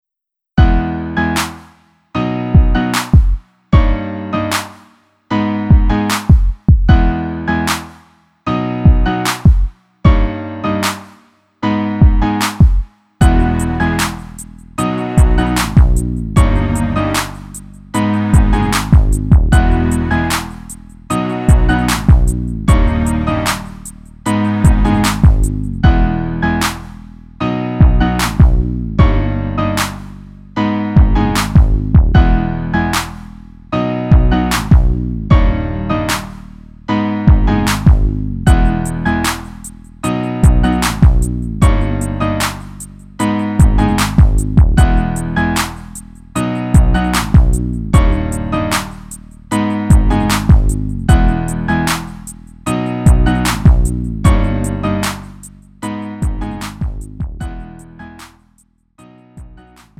음정 원키 4:02
장르 구분 Lite MR